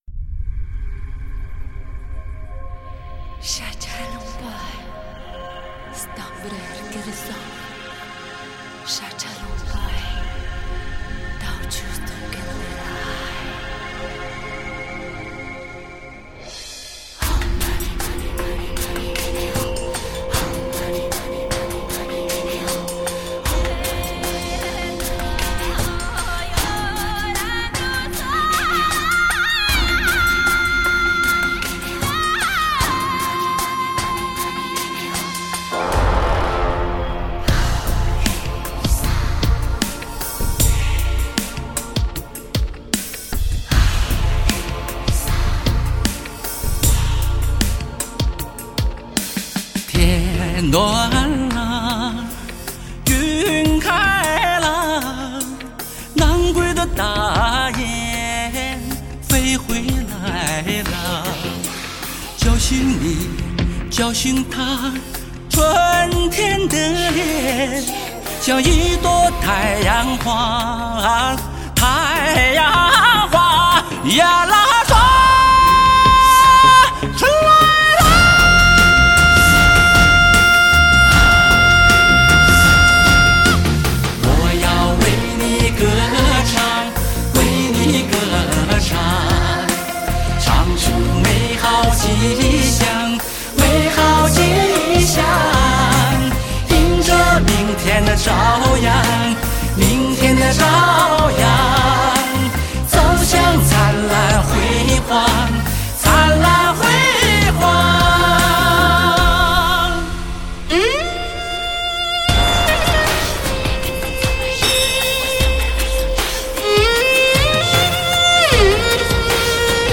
明快、欢畅、高亢，天籁般的高音给人以阳光之美。